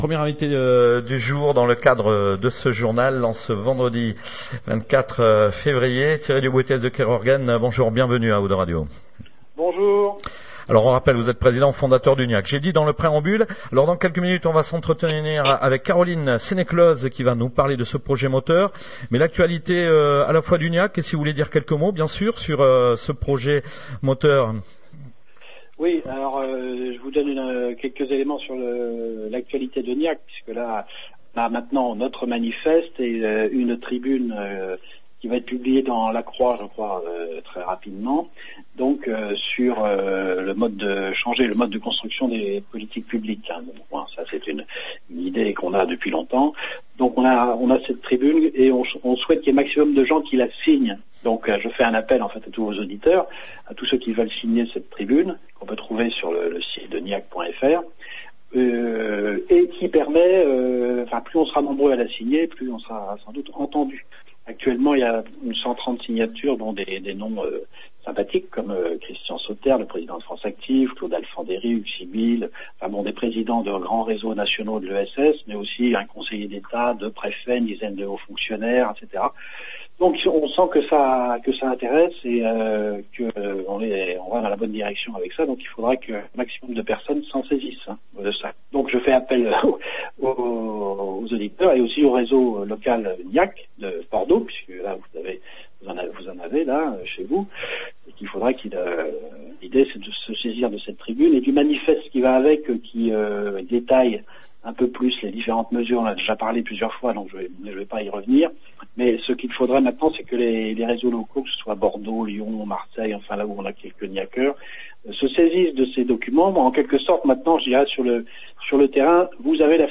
26/02/2017 : Nouvelle interview GNIAC / 02 Radio - projet MOTEUR